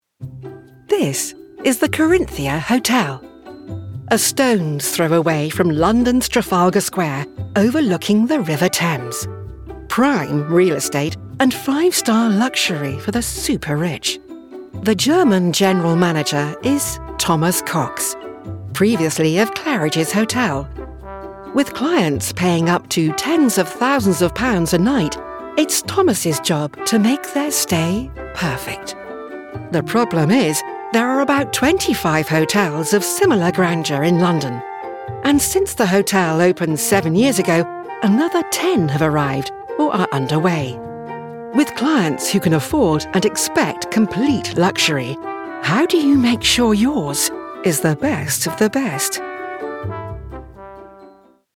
Female
Assured, Engaging, Reassuring, Smooth, Warm
RP - West Country (Gloucestershire - Native) - Southern Irish - Welsh - American English -
Microphone: Sennheiser MK4 - Sennheiser MKH416